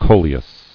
[co·le·us]